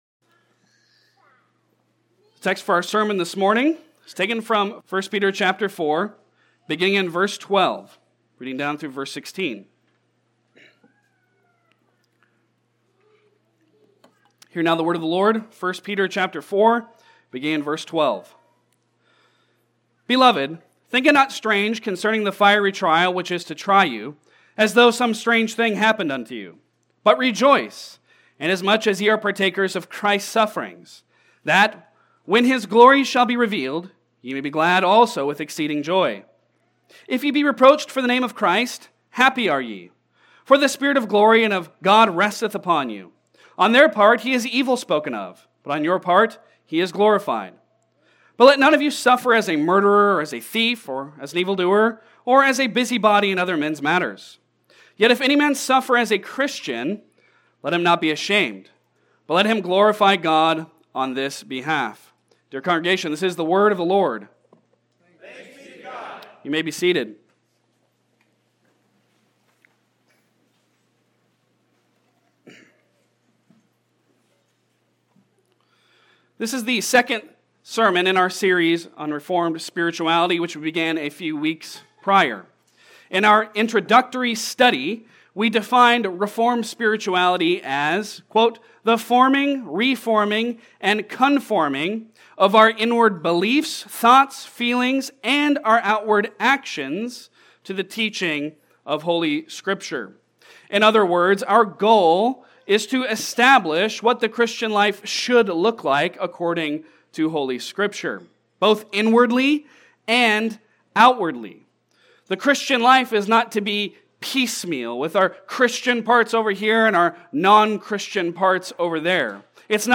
Reformed Spirituality Passage: 1 Peter 4:12-16 Service Type: Sunday Sermon